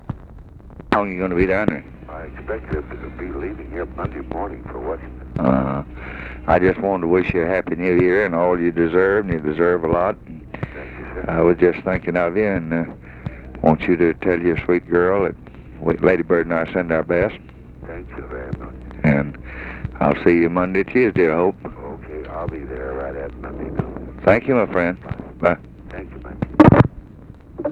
Conversation with EVERETT DIRKSEN, January 2, 1964
Secret White House Tapes